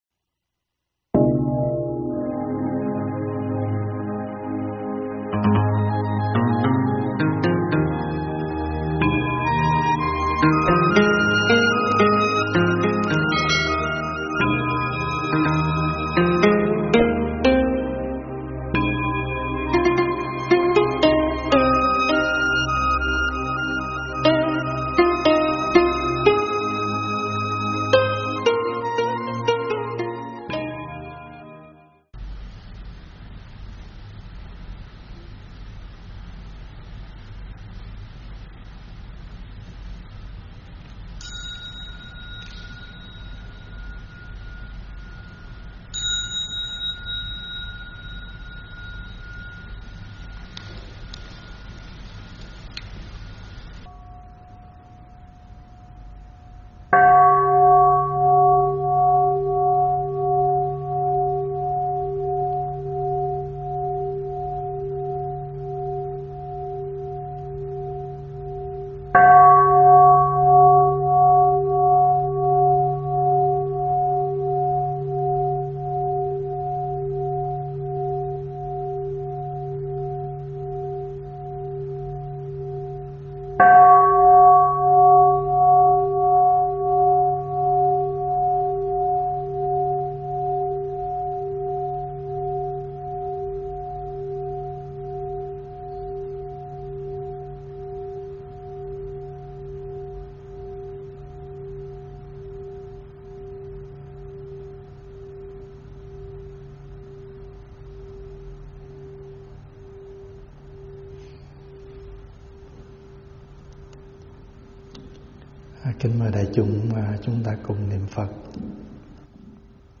giảng tại Tv Trúc Lâm